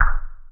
DAN02BD.wav